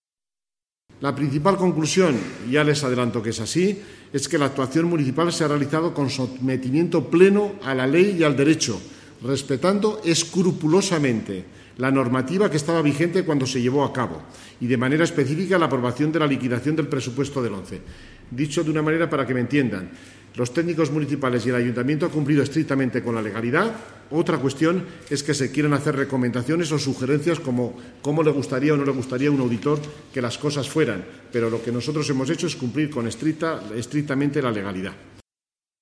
Sobre ello, el vicealcalde y portavoz del Gobierno municipal, Fernando Gimeno, ha manifestado: